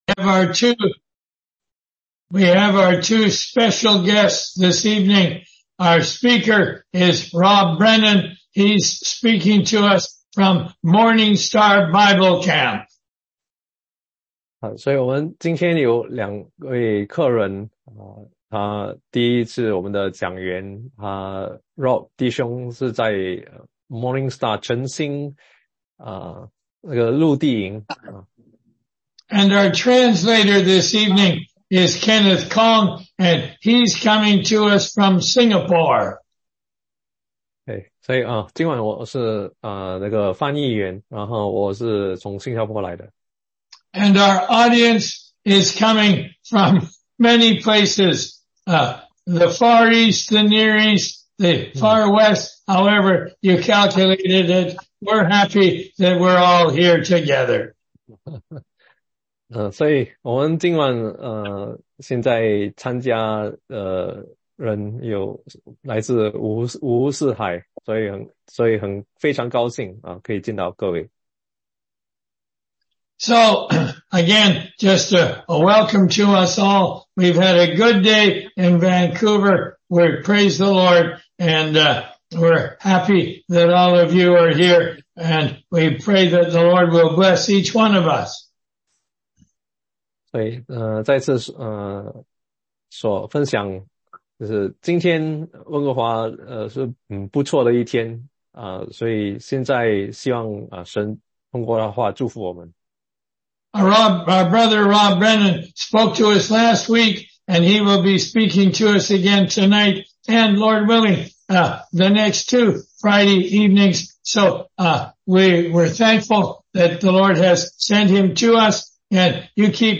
中英文查经